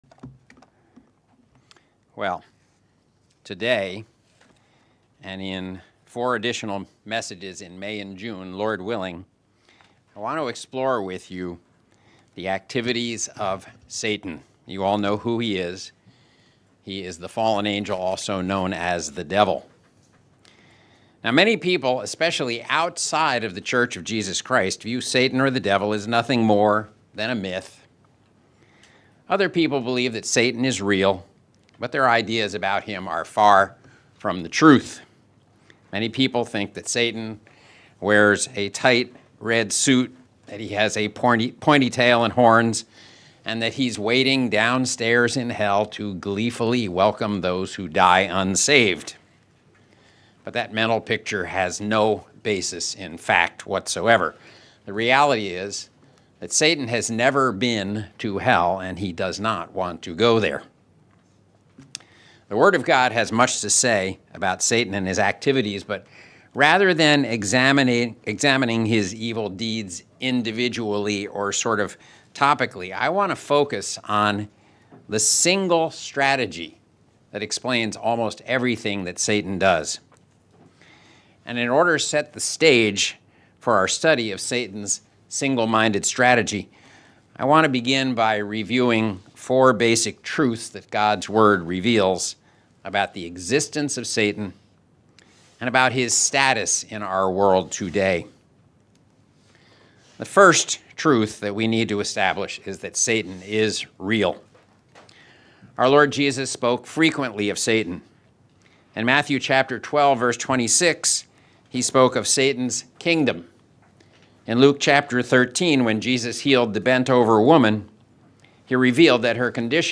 English Worship (LCK) - The War of the Seeds Part 1